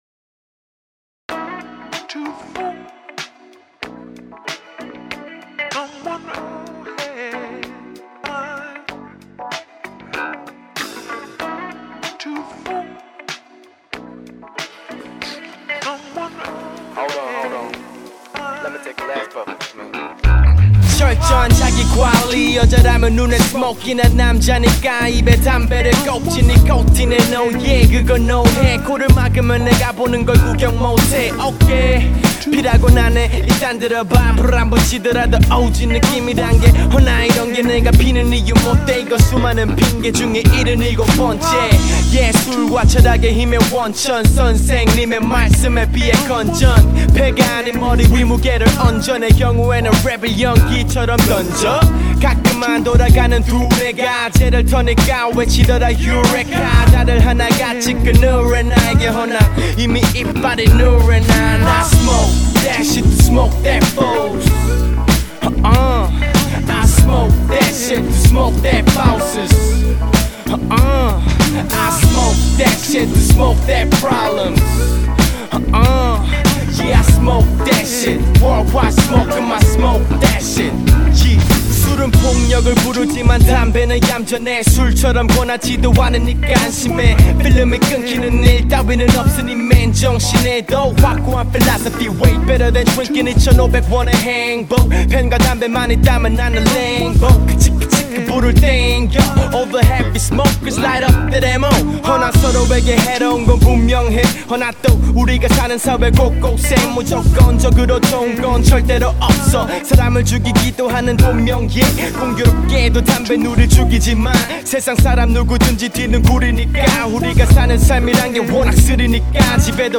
아카펠라 추출이 좀 미숙해서 뒤에 잡음이 좀나네요...
전체적인 분위기도 좋고 샘플 잘 따신거같네요.
기타 소리도 몽롱하고 마지막마디 슬랩베이스도 좋네요.